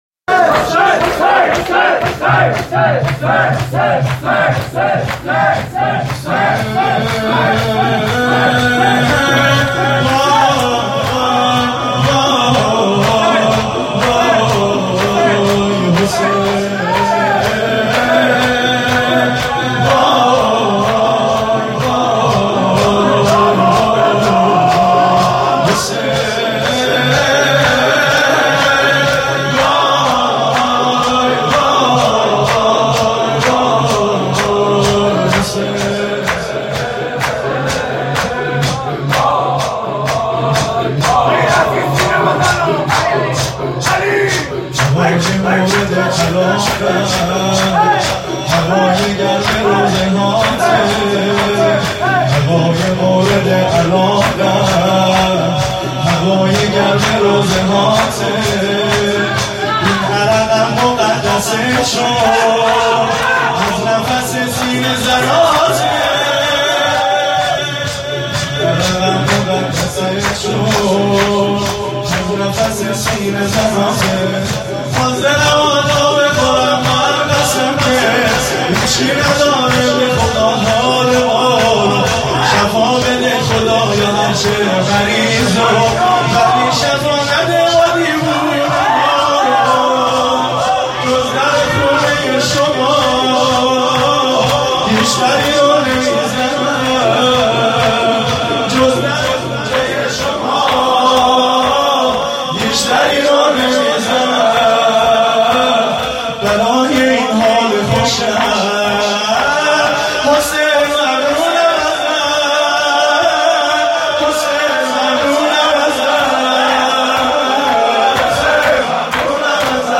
جلسه هفتگی